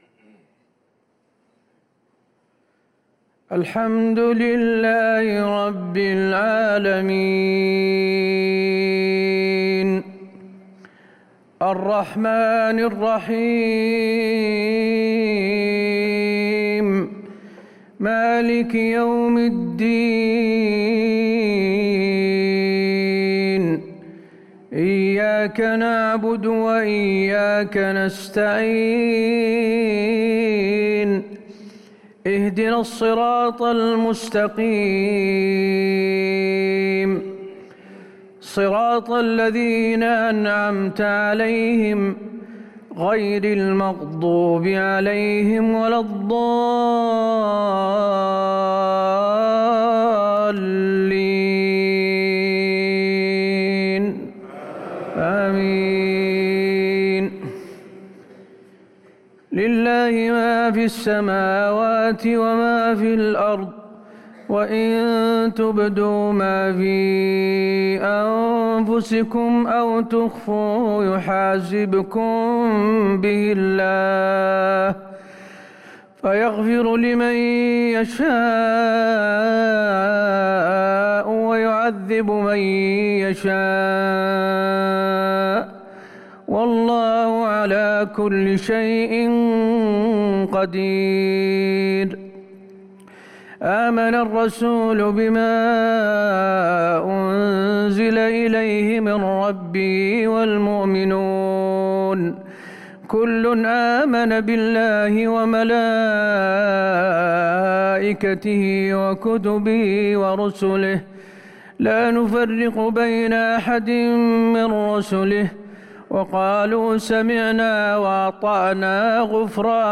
صلاة العشاء للقارئ حسين آل الشيخ 21 رمضان 1444 هـ